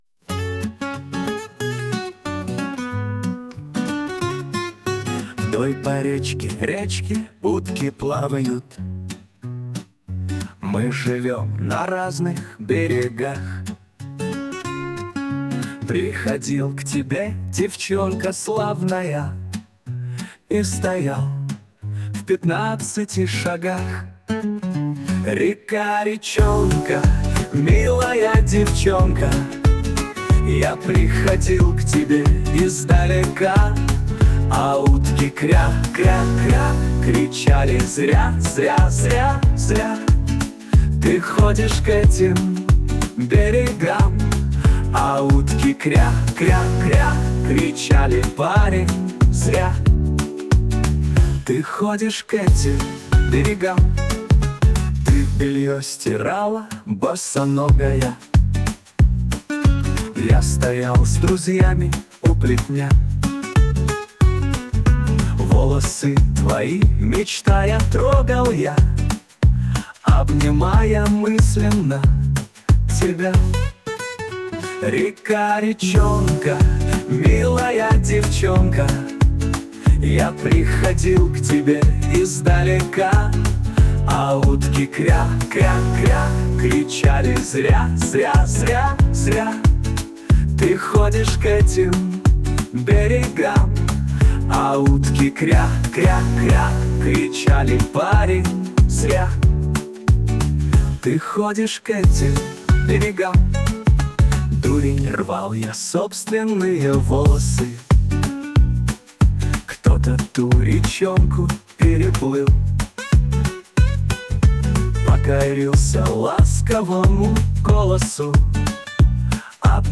Уважаемые Завалинцы, я решил напомнить Вам (а кого-то познакомить) легендарную дворовую песню 1960-х.
Предлагаю вам два варианта, мелодия изменена.